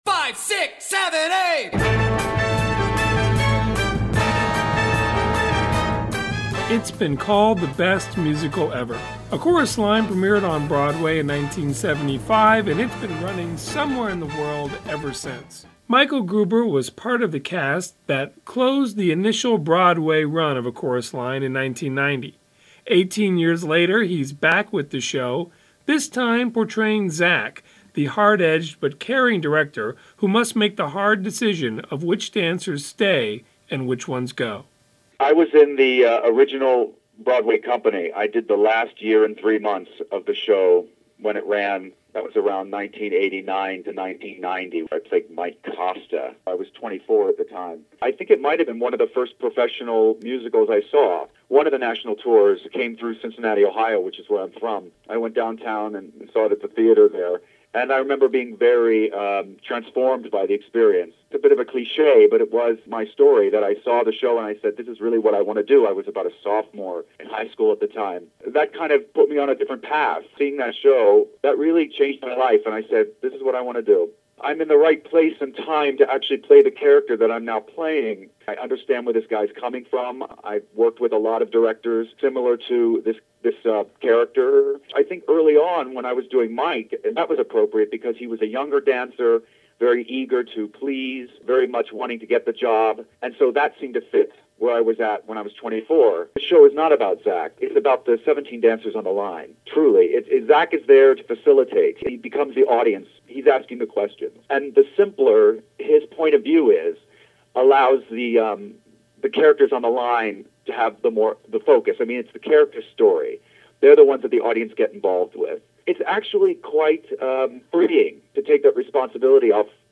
音は、あまりクリアではなく、ちょっと雑音も入っています。